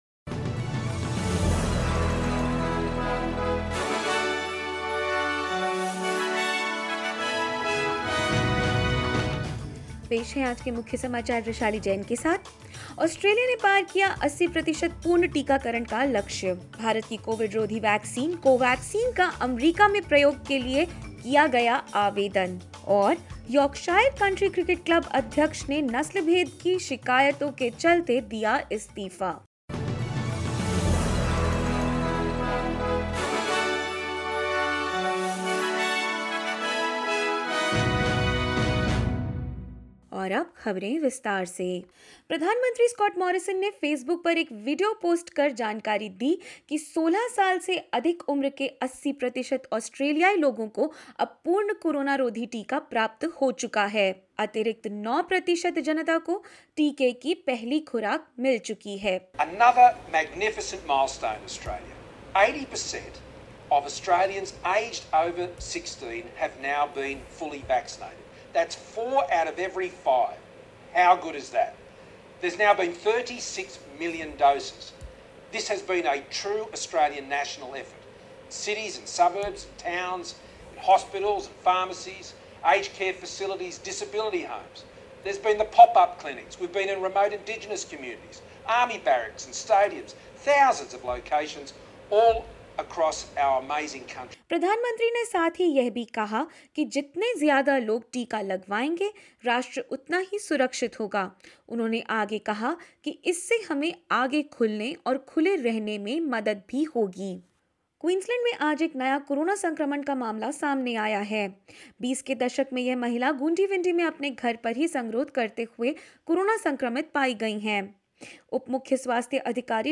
In this latest SBS Hindi news bulletin of Australia and India: 80 per cent Australian residents over-16 are now fully vaccinated; Another community COVID-19 case makes appearance in Queensland, contact tracing underway and more.